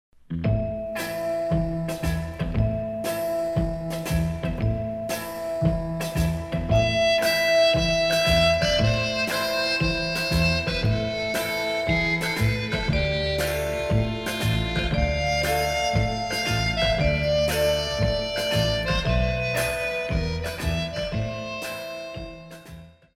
Заставкой к прогнозу погоды в конце 80-х была мелодия